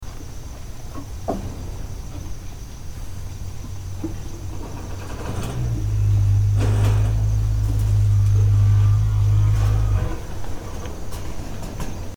В частности, живущие в зоопарке слоны имитировали звуки
проезжающих мимо грузовиков, а также «чириканье» соседей по зоопарку — азиатских слонов.
pairedtruckMlaikacall_1981.mp3